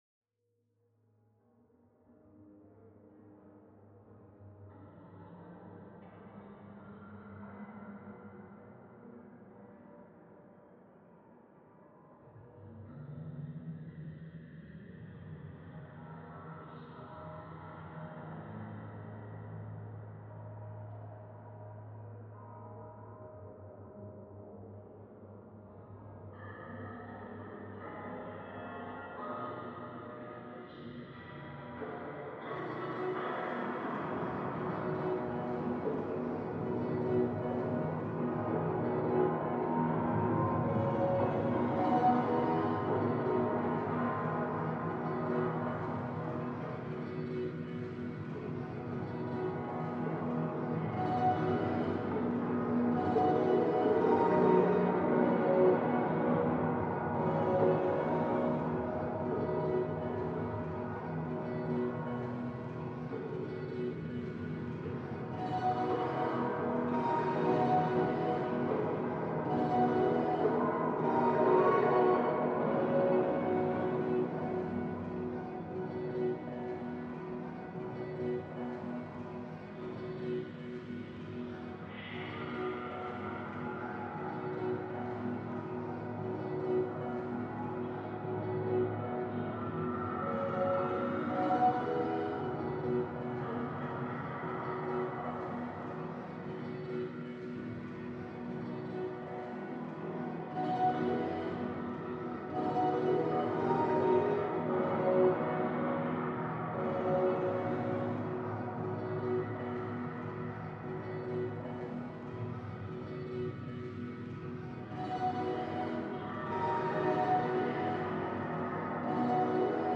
Essaouira gnaoua reimagined